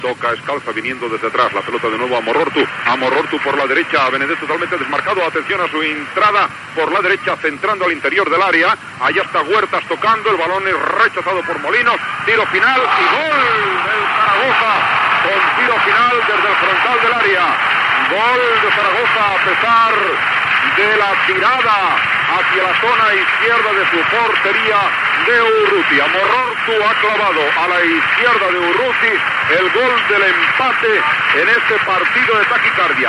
Narració del gol de l'empat del Saragossa.
Esportiu